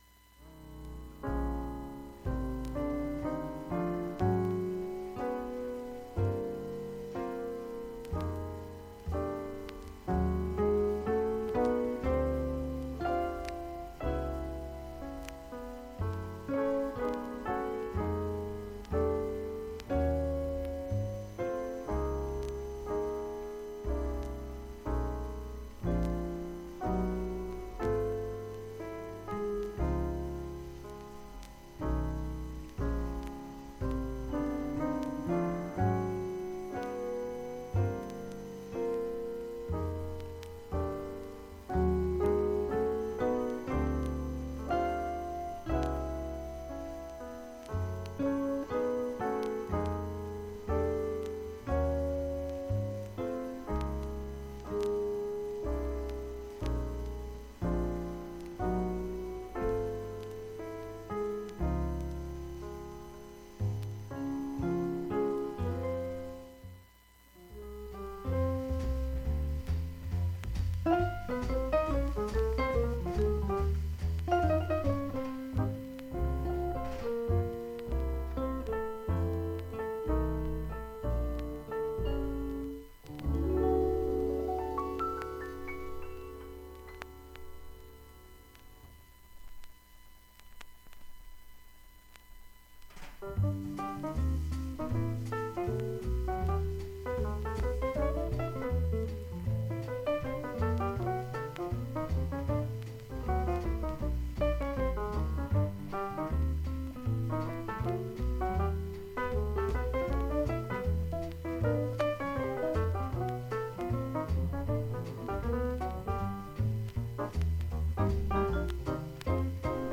B面は音質良好です全曲試聴済み。
バックチリなども無くいい音質です。
単発のかすかなプツが28箇所
◆ＵＳＡ盤オリジナル Mono